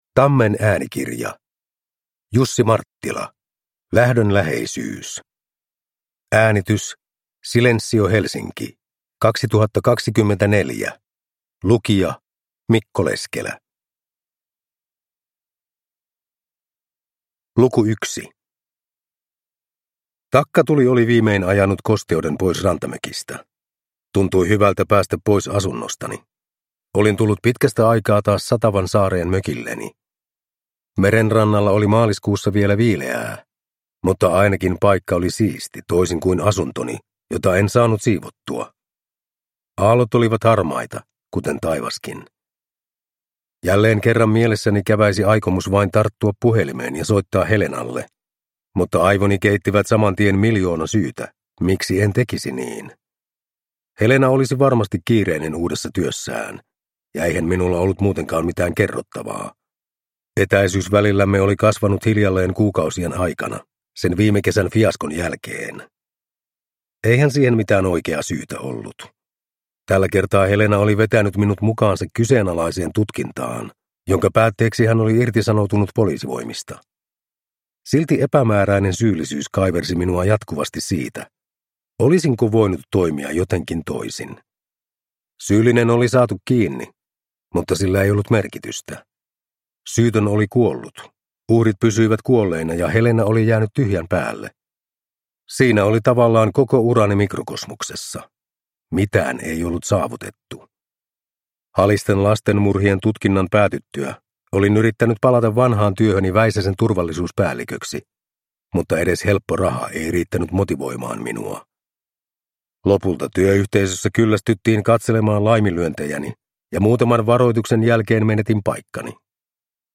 Lähdön läheisyys (ljudbok